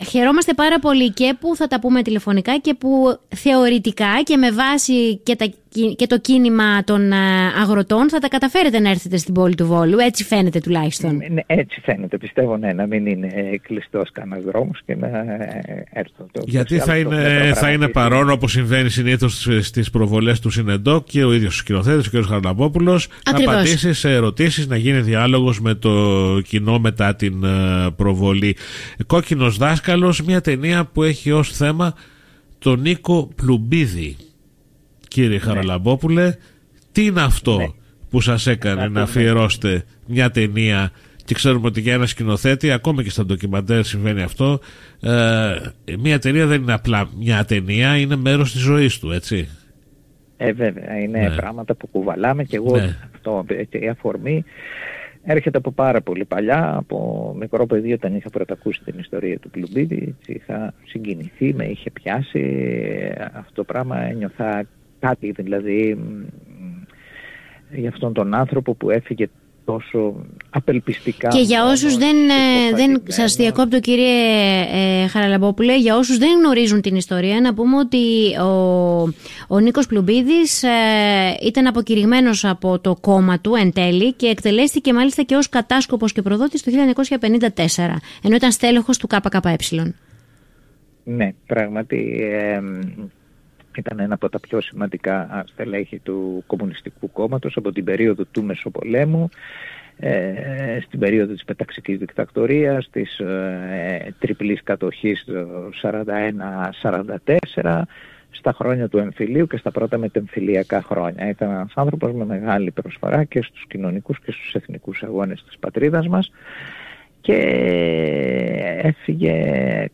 μίλησε στην εκπομπή του FORMEDIA RADIO 99.8